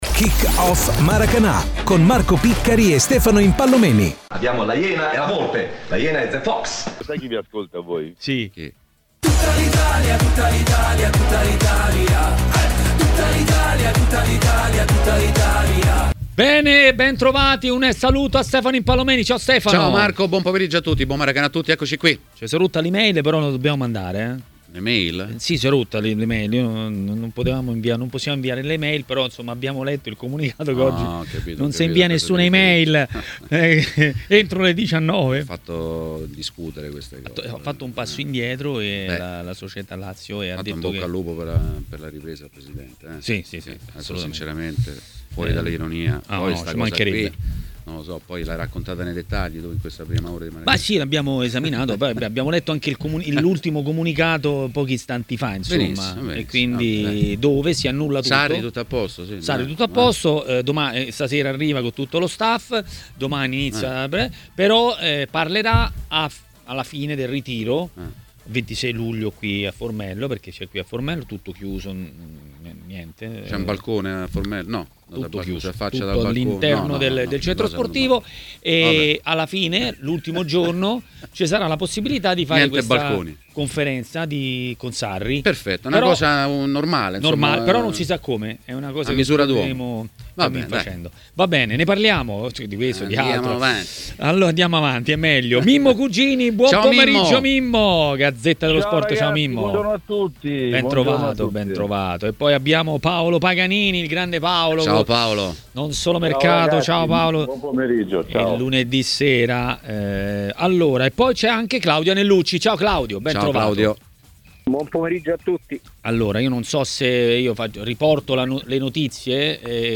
è stato ospite di TMW Radio, durante Maracanà.